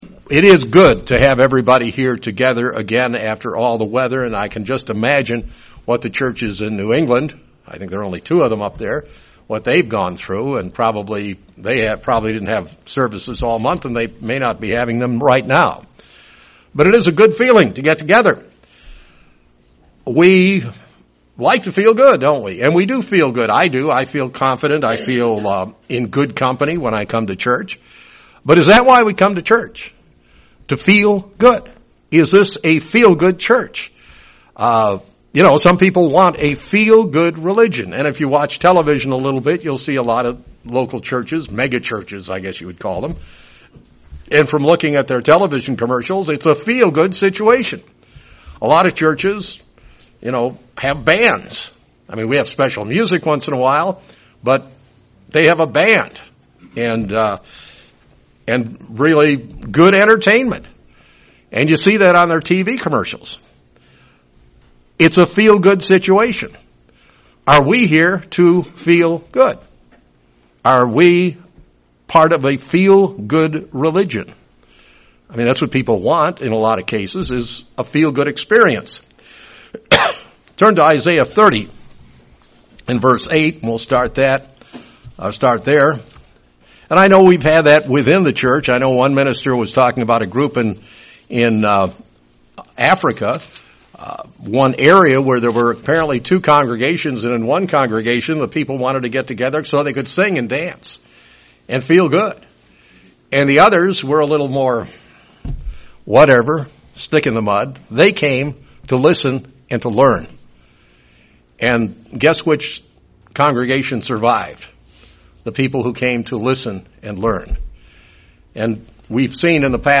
We are called to do good, even if it doesn't always feel good. This sermon looks into the subject of feel good religion.